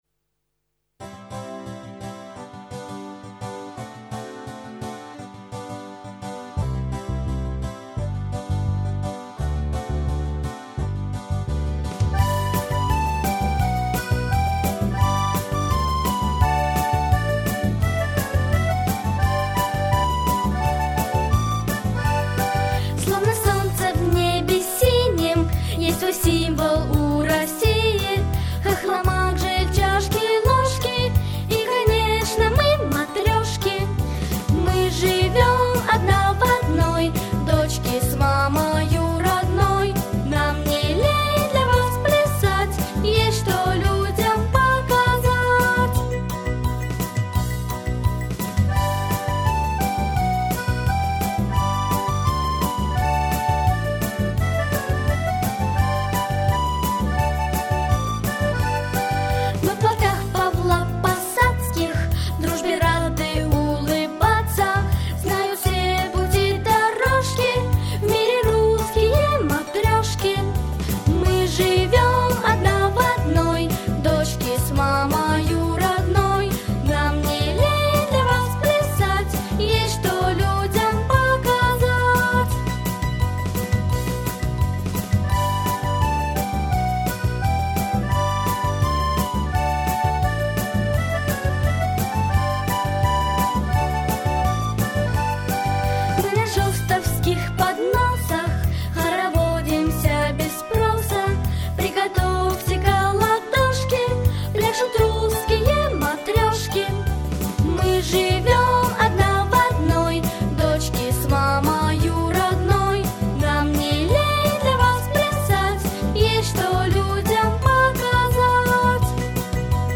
• Качество: Хорошее
• Жанр: Детские песни